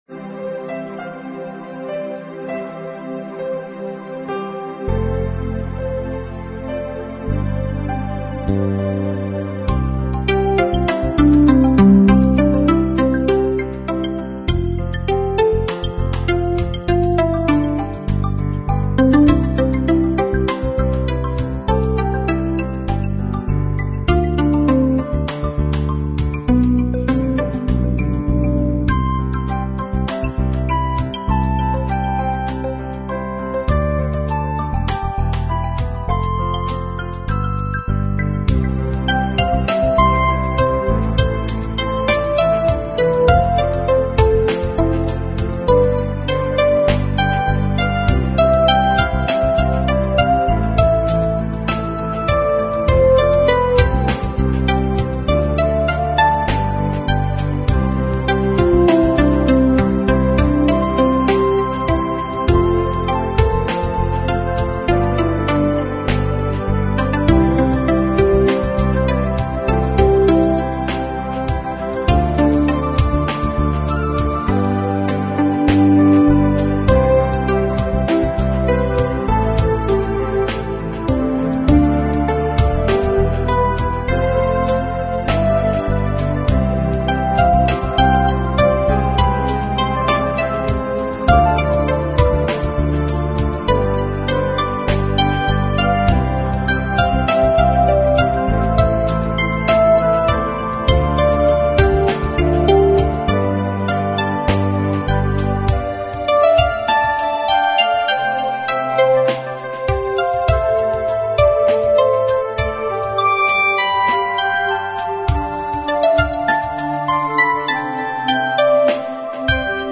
Relaxed New Age Composition